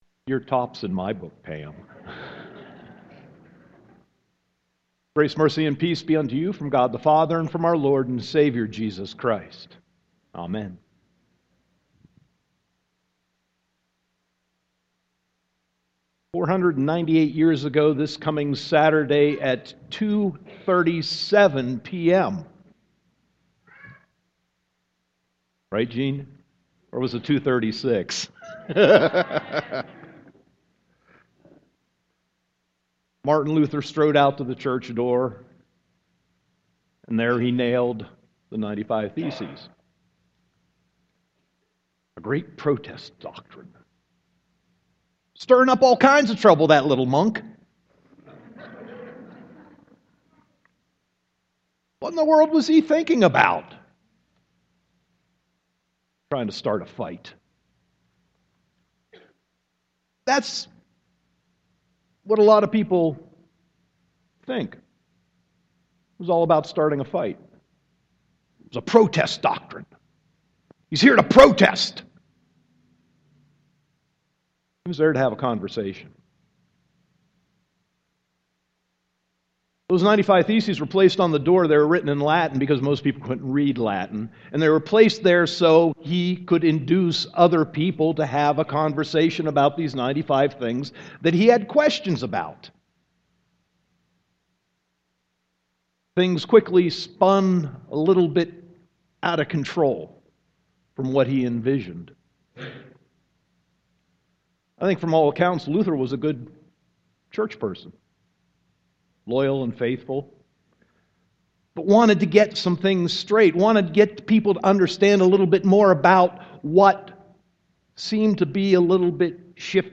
Sermon 10.25.2015